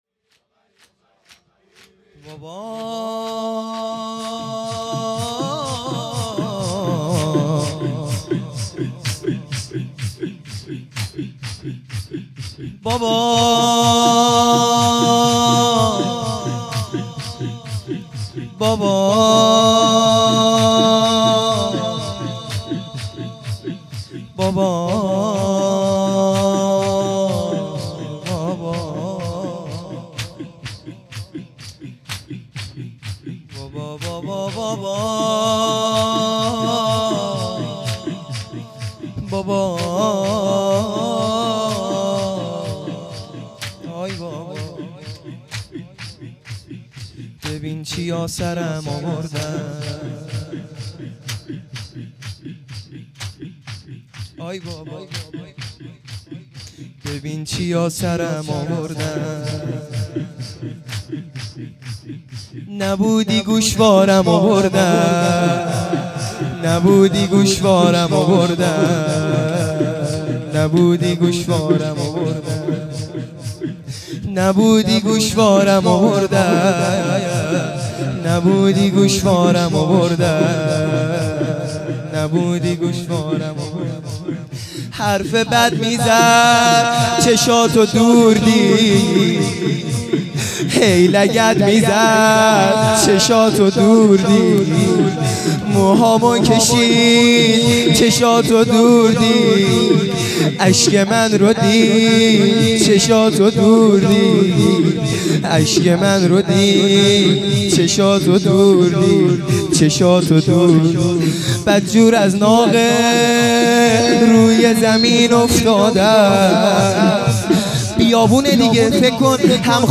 لطمه زنی | ببین چیا سرم آوردن